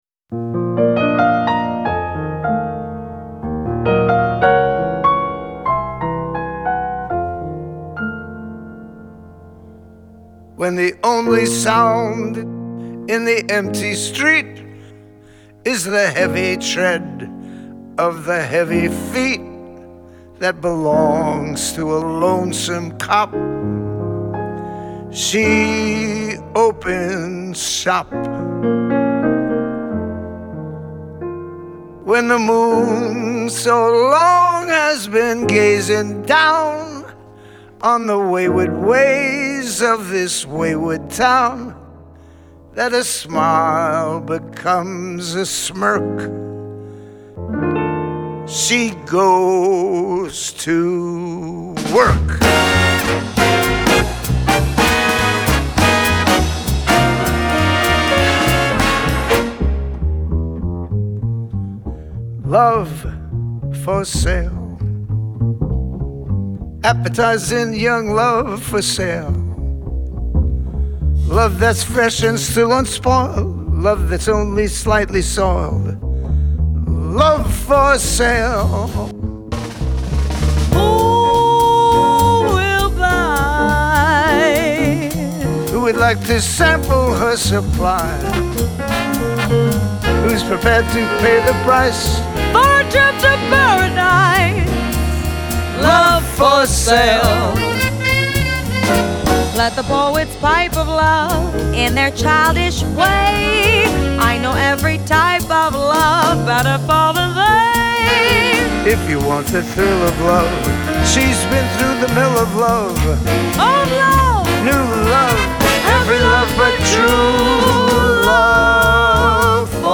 Genre : Vocal Jazz